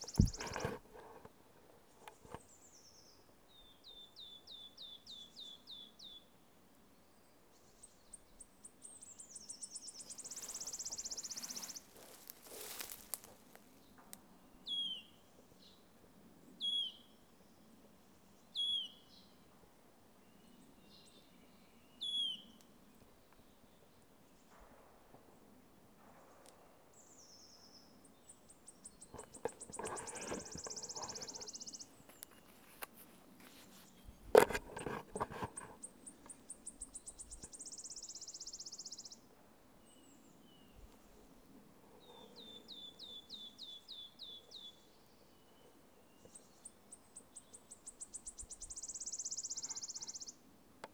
pouillot_siffleur_lo..> 2022-03-01 17:48  8.6M